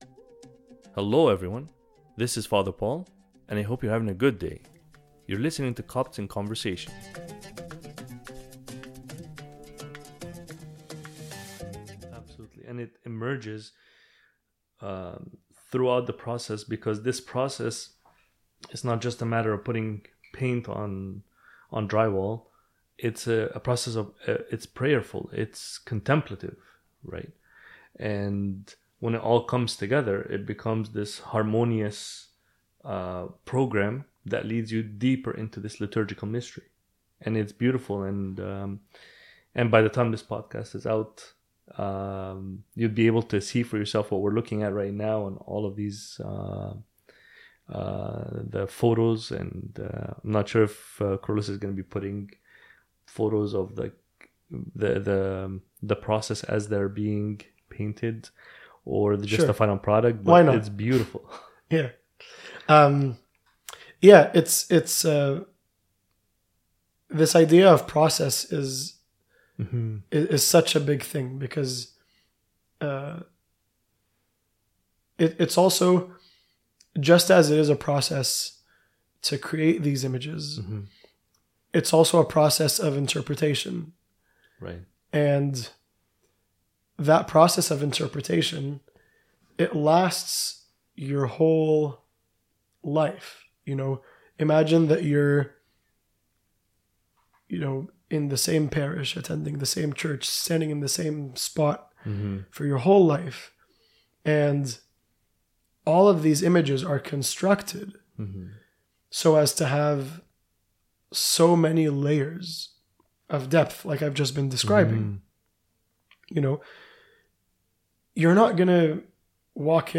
This episode is Part 2 of my conversation